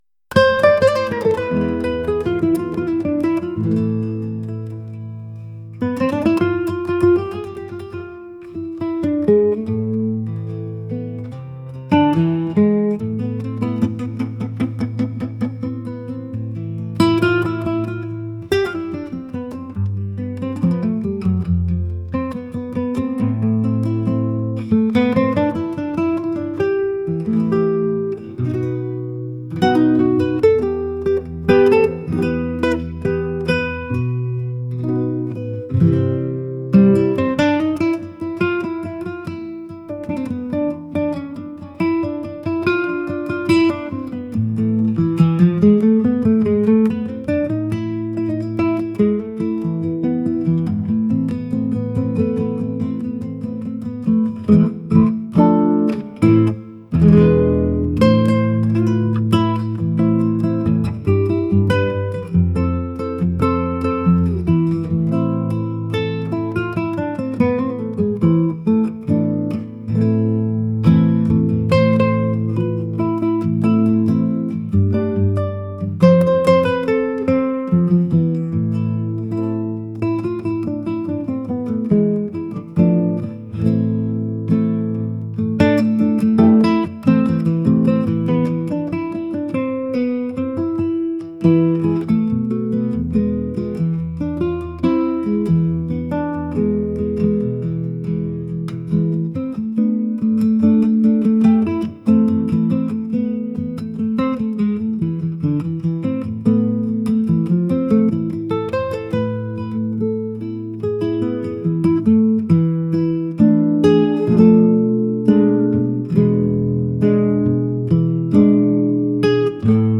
world | romantic | traditional